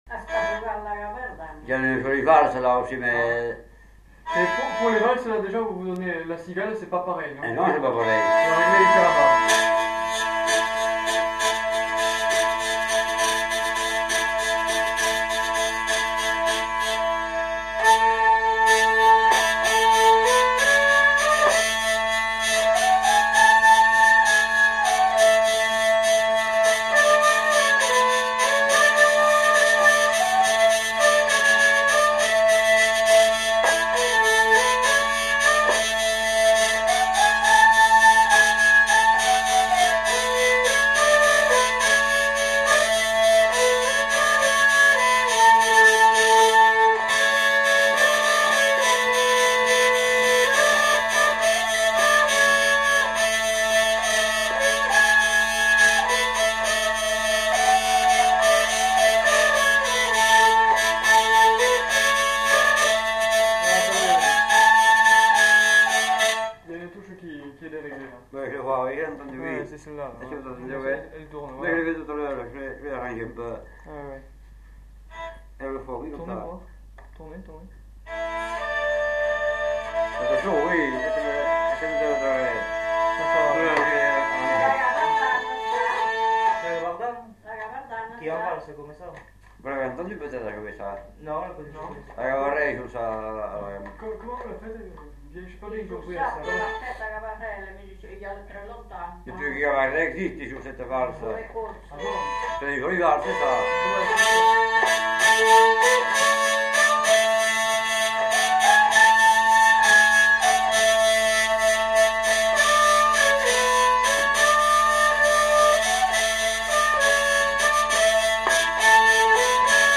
Aire culturelle : Gabardan
Lieu : Herré
Genre : morceau instrumental
Instrument de musique : vielle à roue
Danse : valse